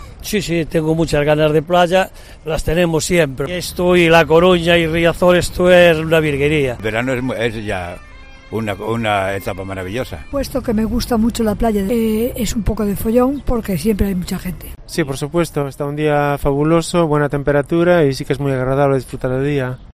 Bañistas en la playa de Riazor: "la playa es una v¡rguería"